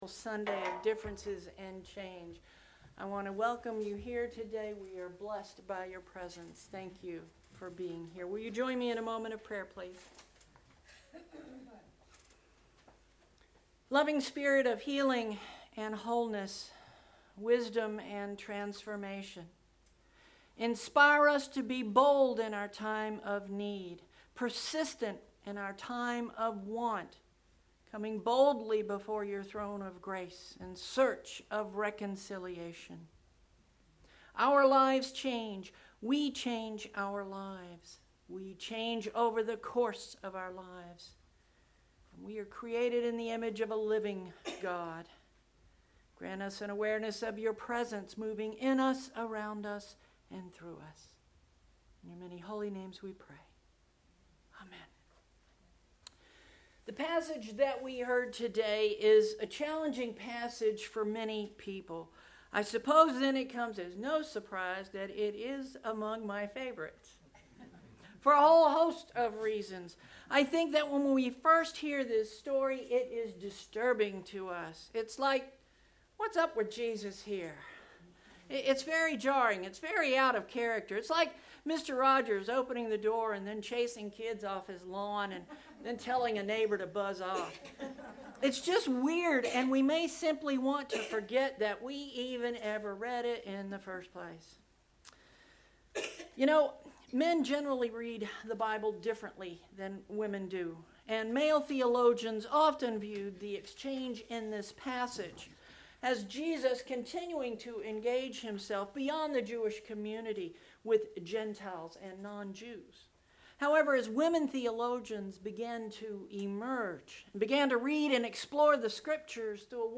09/09 Sermon Posted